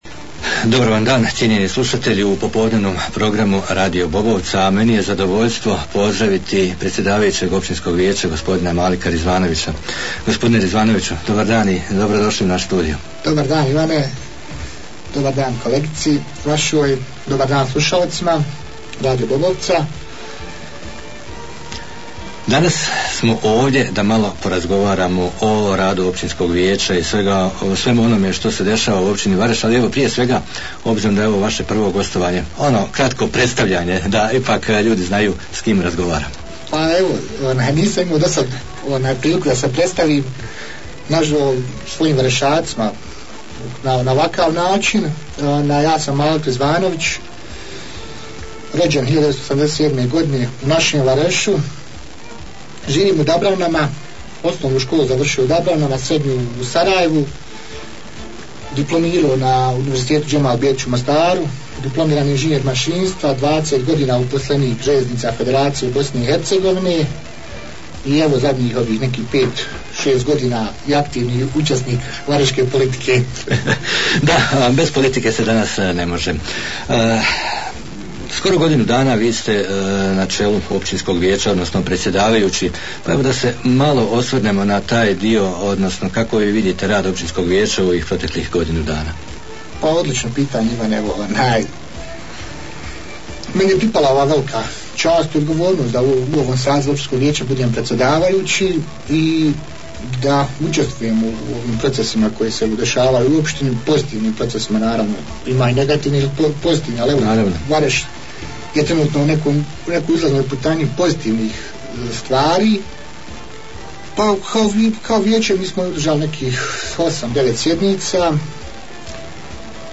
Naš današnji gost bio je predsjedavajući Općinskog vijeća Vareš Malik Rizvanović, a razgovarali smo o radu OV- Vareš u proteklom razdoblju i o aktualnoj situaciji u općini Vareš.